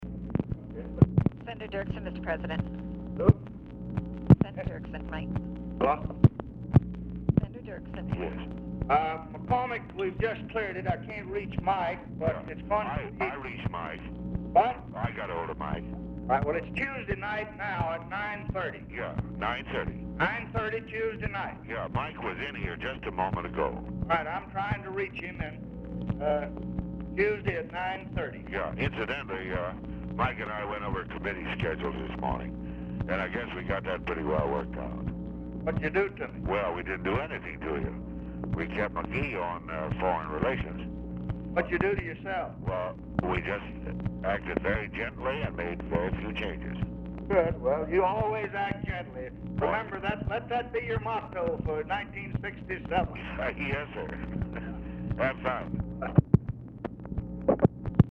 Telephone conversation # 11322, sound recording, LBJ and EVERETT DIRKSEN, 1/7/1967, 12:30PM
POOR SOUND QUALITY
Format Dictation belt